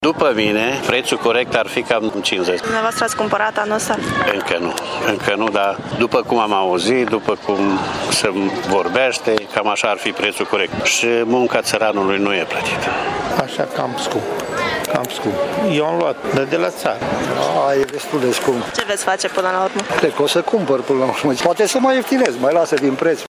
Unii dintre ei renunță la carnea de miel, din cauza prețului ridicat: